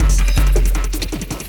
53 LOOP 01-L.wav